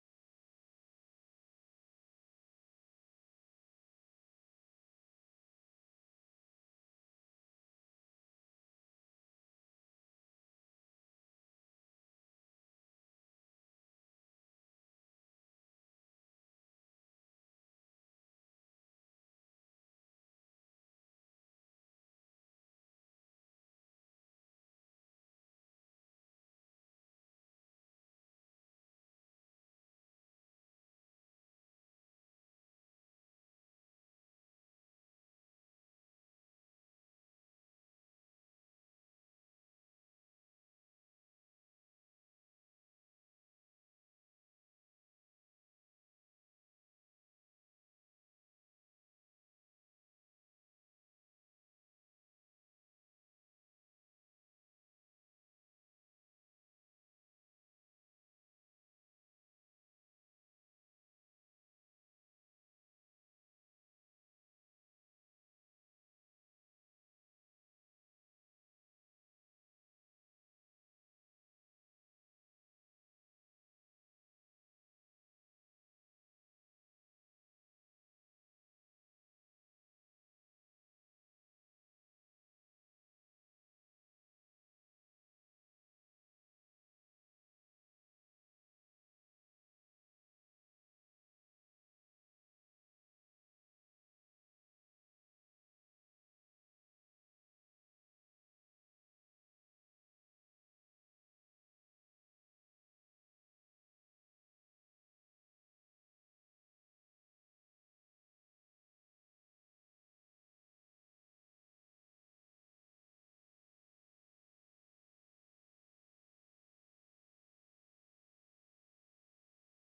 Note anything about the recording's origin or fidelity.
Join us for our weekly service in-person or online starting at 10 A.M. every Sunday.